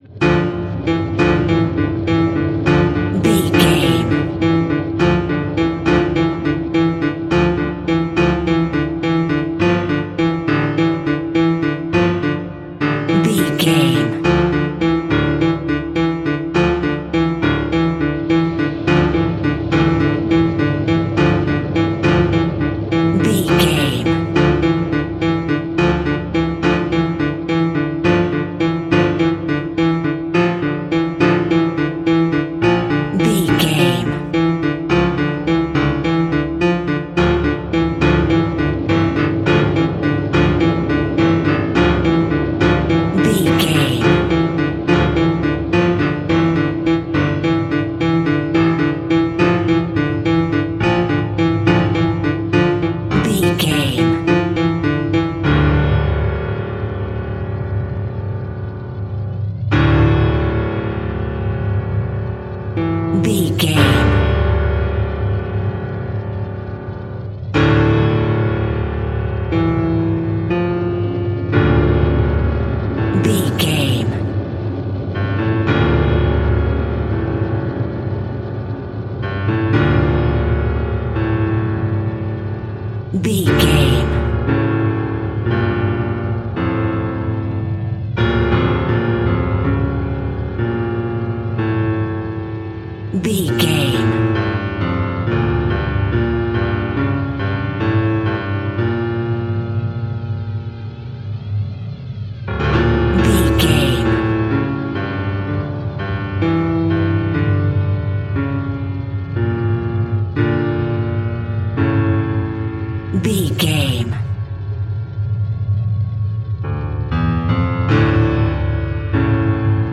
Aeolian/Minor
Fast
tension
ominous
dark
eerie
piano
synthesizers
Synth Pads
atmospheres